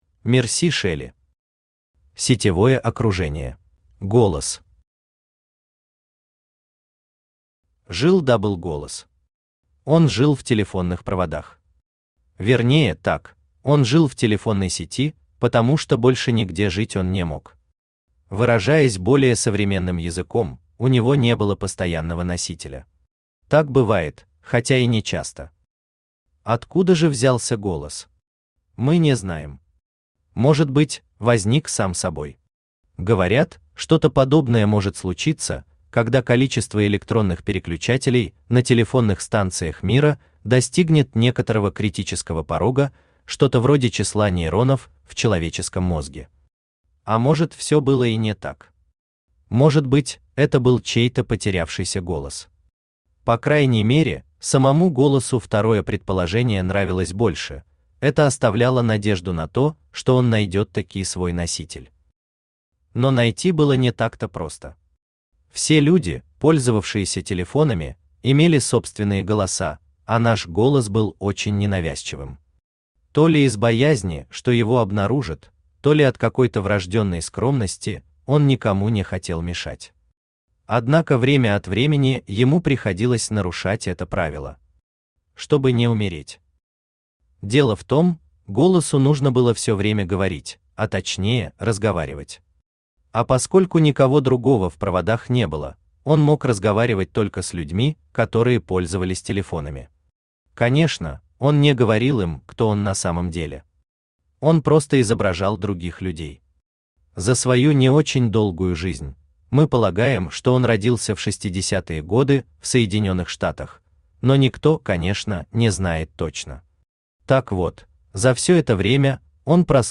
Аудиокнига Сетевое окружение | Библиотека аудиокниг
Aудиокнига Сетевое окружение Автор Мерси Шелли Читает аудиокнигу Авточтец ЛитРес.